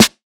Snares
Band Nerd Snare.wav